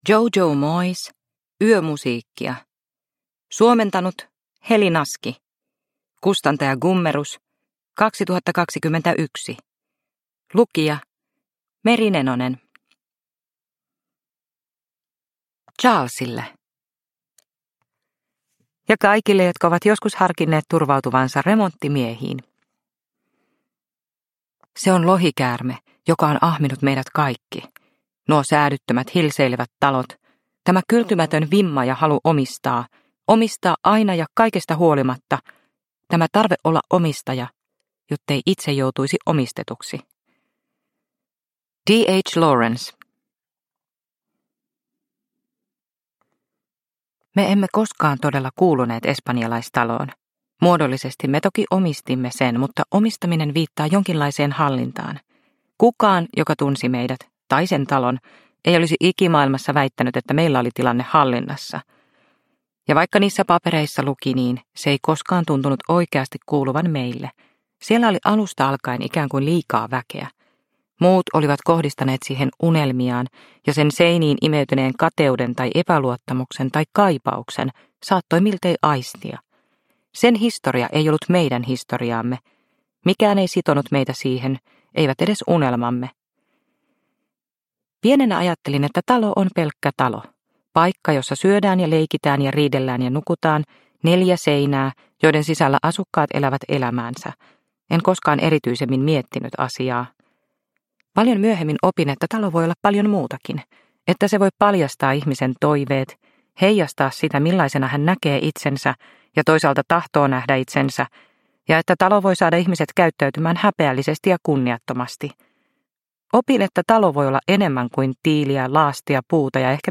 Yömusiikkia – Ljudbok – Laddas ner